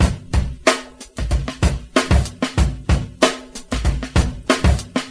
• 94 Bpm Drum Beat D Key.wav
Free drum loop - kick tuned to the D note. Loudest frequency: 1227Hz
94-bpm-drum-beat-d-key-uPW.wav